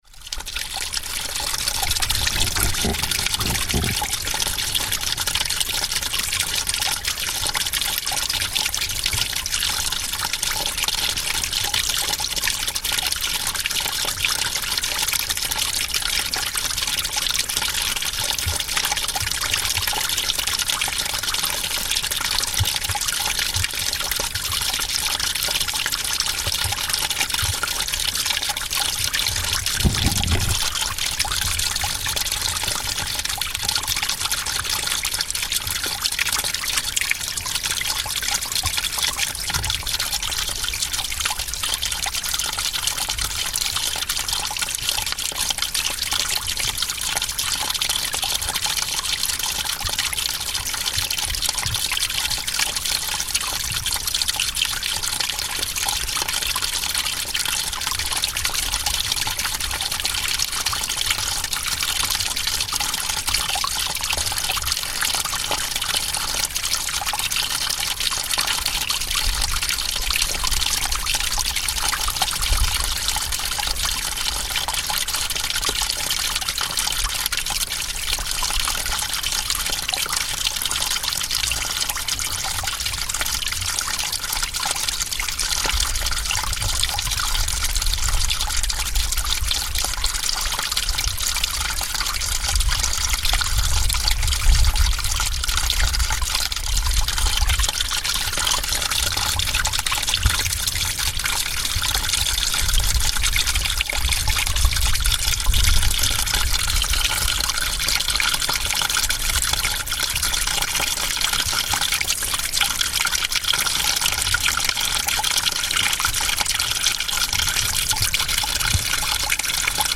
Atop the famous Quiraing, after a climb of hundreds of metres, we reach the summit, with incredible views on all sides.
Here we record the sound of a small stream, which are frequent across the surface of the plateau at the top of the Quiraing, and contribute to the sometimes-boggy going underfoot. Recorded on the Isle of Skye, Scotland by Cities and Memory, April 2025.